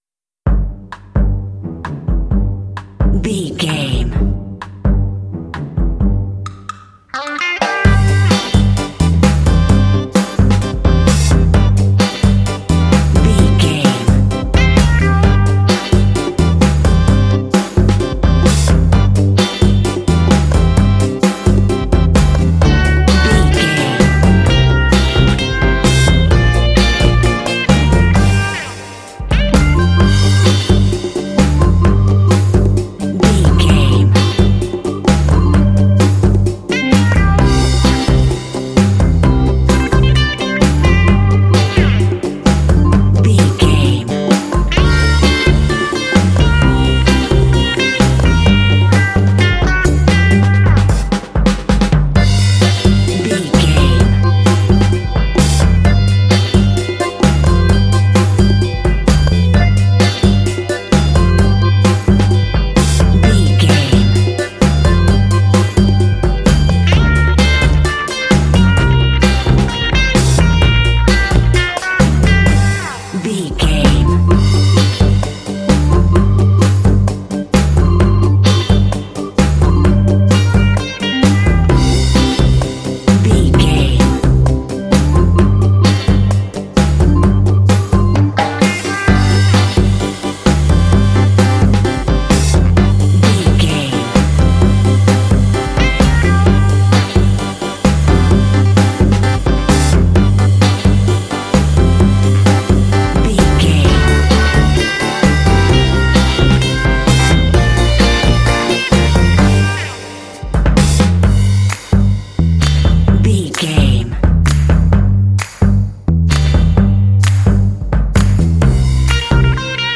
Uplifting
Aeolian/Minor
funky
smooth
groovy
driving
happy
bright
drums
brass
electric guitar
bass guitar
organ
percussion
conga
rock
Funk